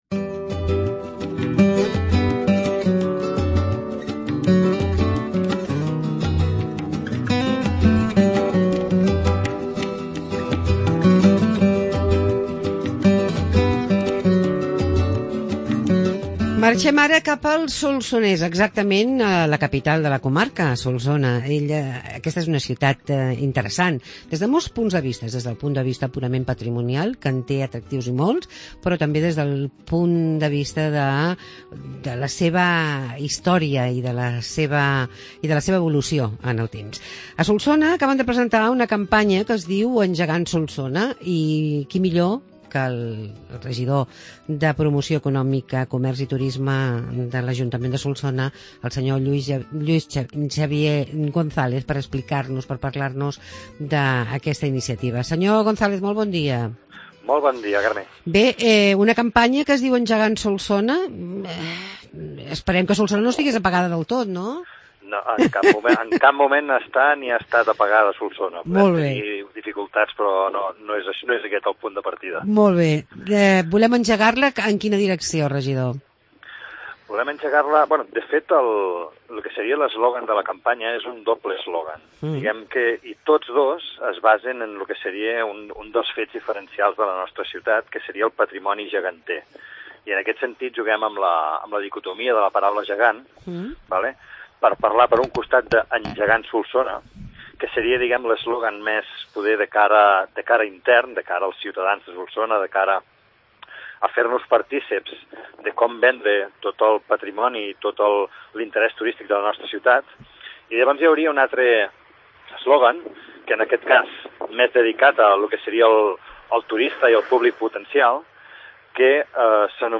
Conèixer "Engegant Solsona" una proposta de l'Agencia Catalana de Turisme. Parlem amb el regidor de Turisme, Lluis-Xavier González